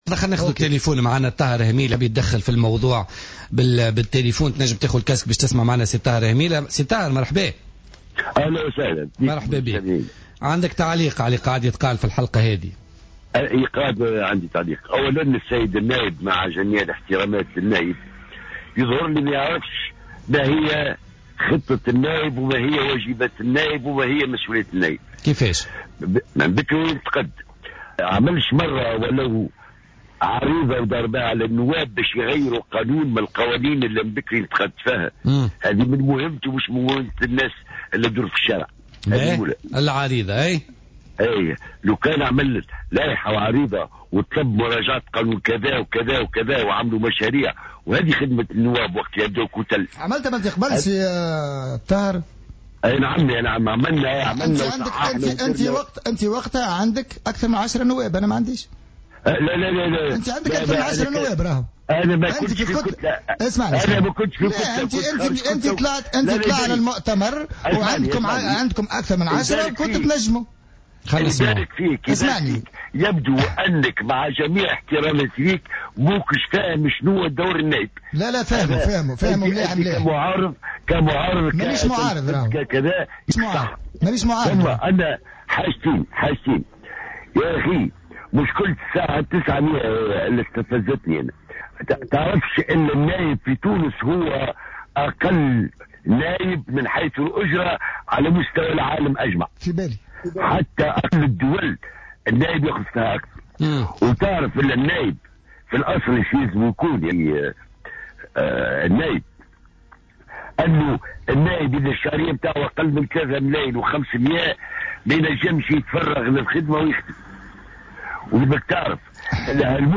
قال الطاهر هميلة النائب السابق بمجلس نواب الشعب في تصريح للجوهرة أف أم في برنامج بوليتكا لليوم الأربعاء 03 مارس 2016 ردا على اعتراض فيصل التبيني على الـ900 دينار التي تم إقرارها للنواب إن أجر النائب في تونس يعد الأقل مقارنة بأجور النواب في العالم.